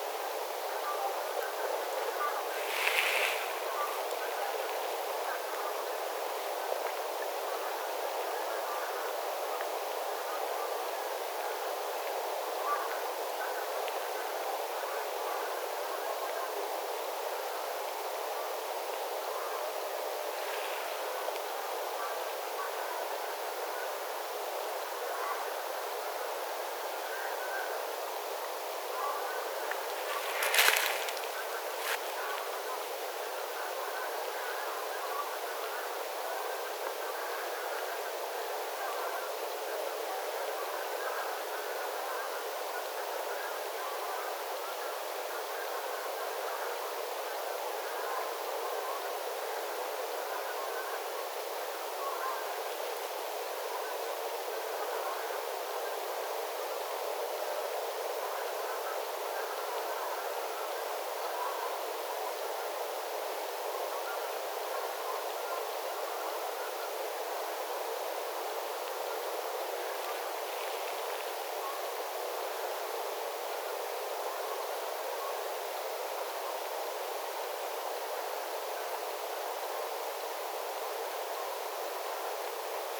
metsähanhiparven ääntelyä.
metsähanhiparvi saapuu
sinne isolle lahdelle lepäämään??